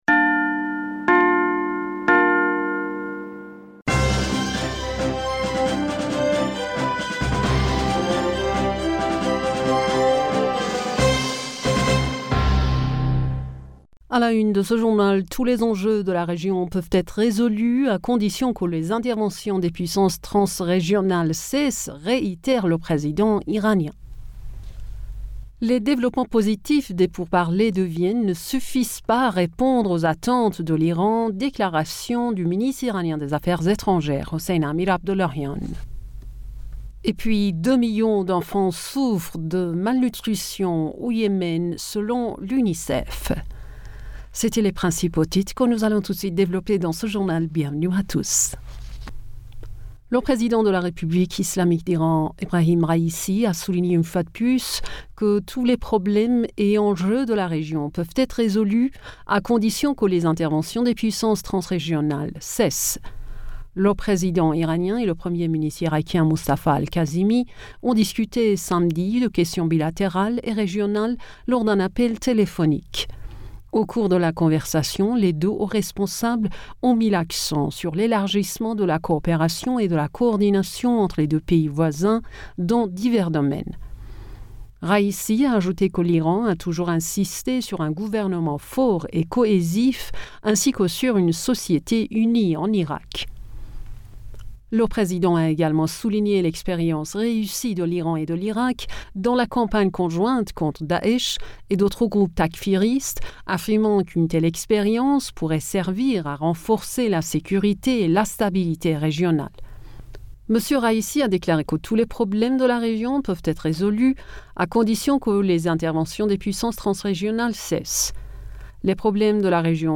Bulletin d'information Du 06 Fevrier 2022